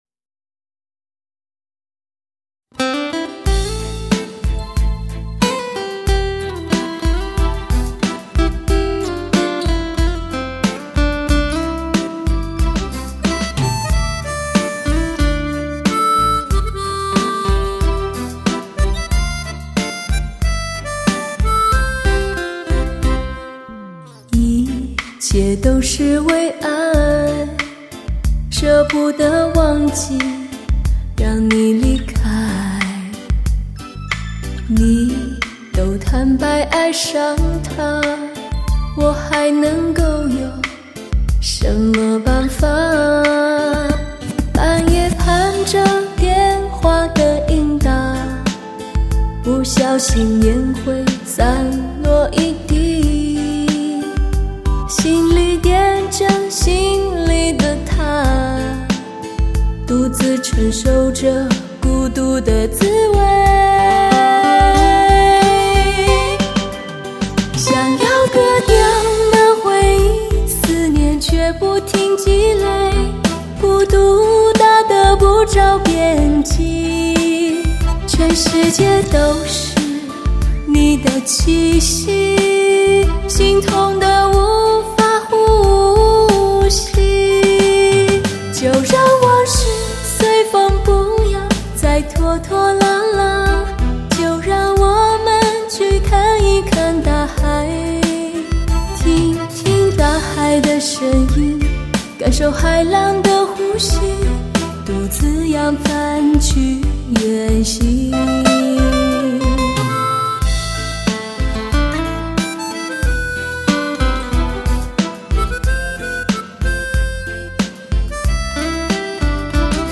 温柔、清亮、略带力道、用情至深的磁性女声，
联袂痴情、真挚感人、温柔中透着坚定的磁性男声，
抒情动人，渗透肺腑，无一不让人动容。